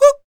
Index of /90_sSampleCDs/ILIO - Vocal Planet VOL-3 - Jazz & FX/Partition G/8 FEM PERC 2